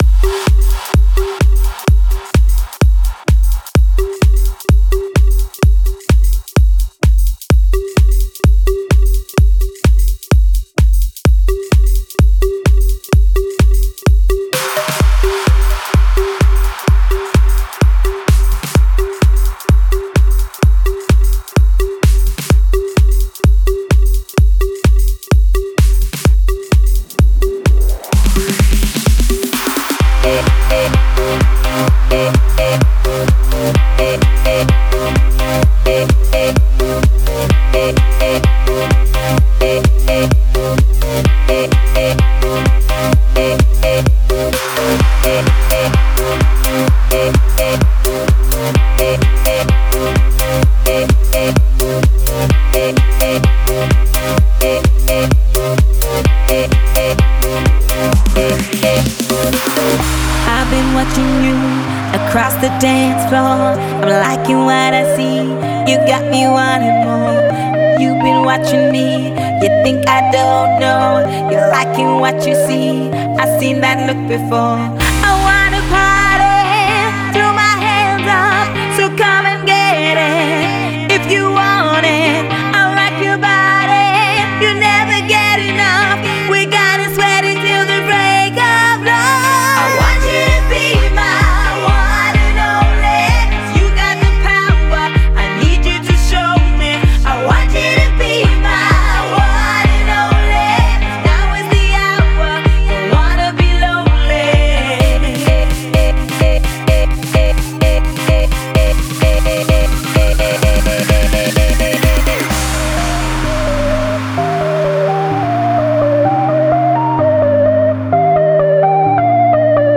egy igazi könnyed pop sláger